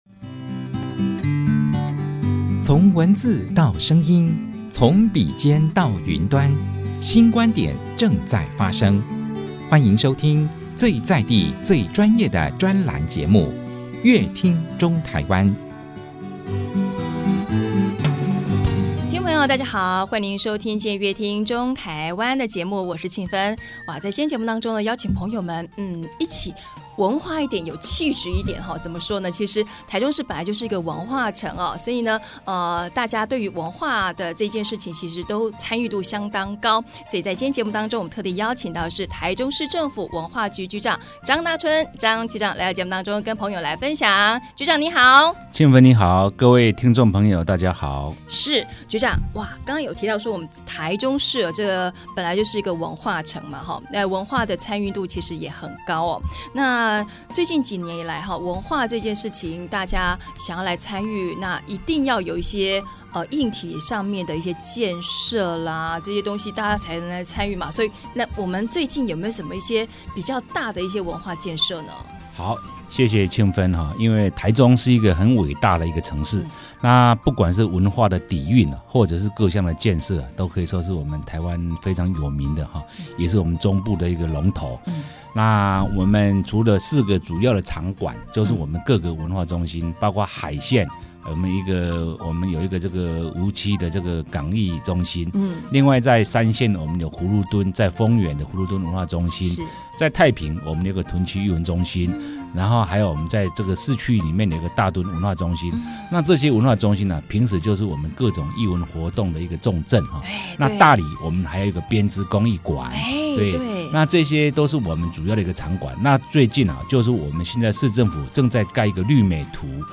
本集來賓：臺中市政府文化局張大春局長 本集主題：「從民眾需求中 提升大台中文化底蘊」